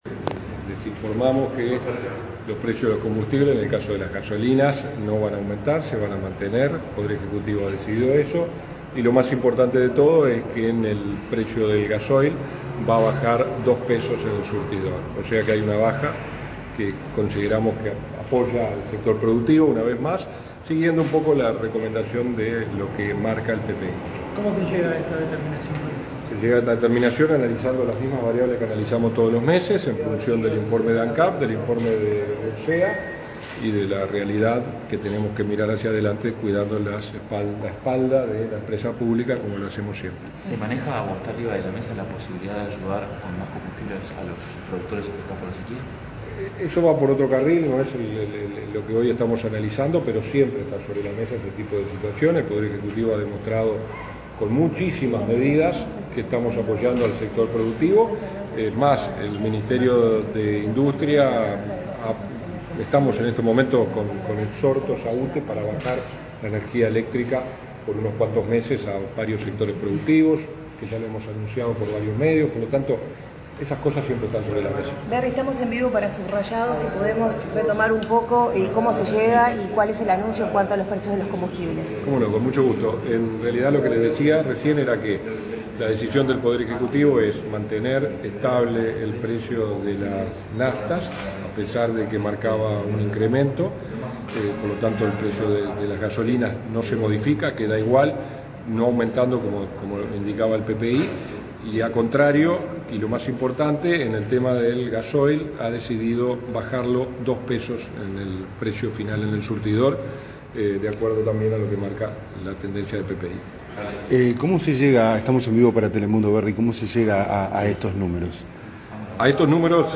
Declaraciones del subsecretario de Industria, Walter Verri
El subsecretario de Industria, Walter Verri, informó a la prensa sobre el ajuste de combustibles correspondiente al mes de marzo.